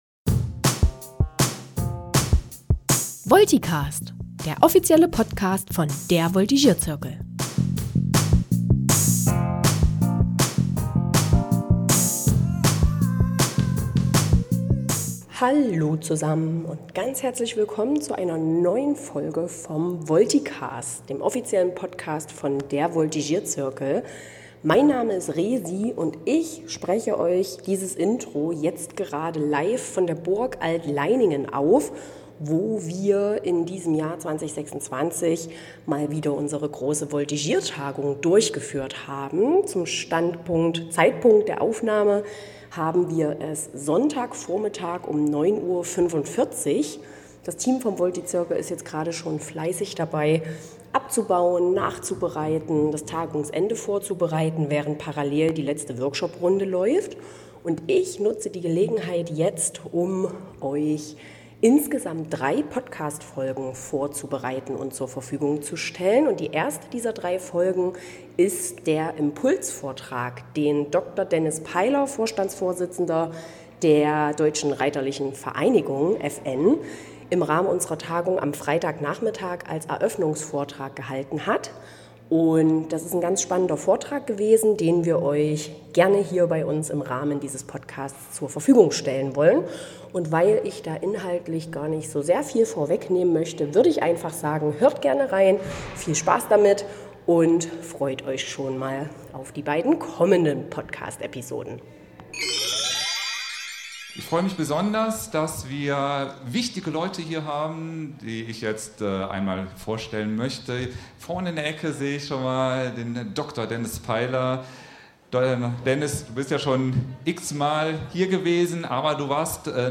Zudem gibt’s am Ende des Vortrages noch eine Überraschung in Form einer ganz besonderen Auszeichnung.